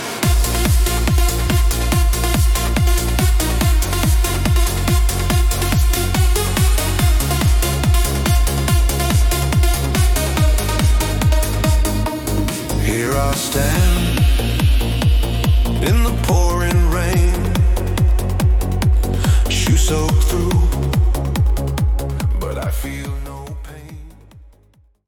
Style : Trance, Eurodance